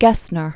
(gĕsnər), Conrad 1516-1565.